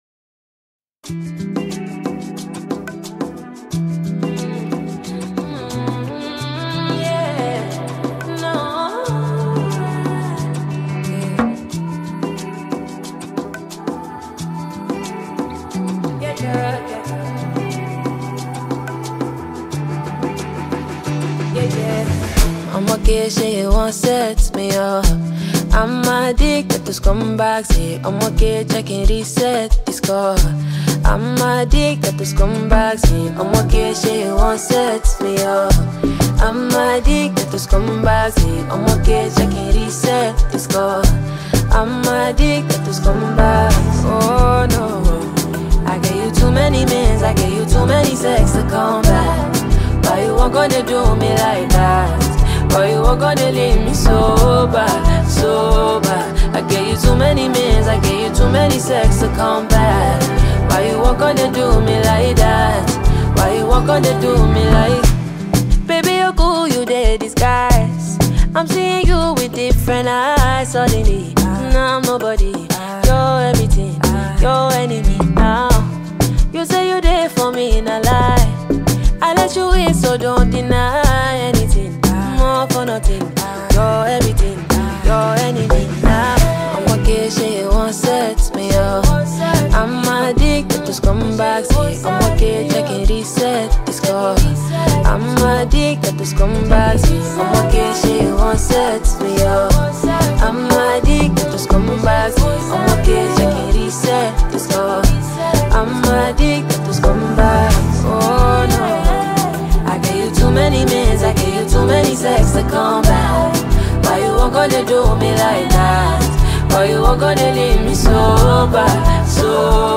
electrifying single
soulful vocals